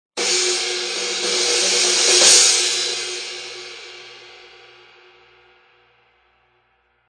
Loud Hit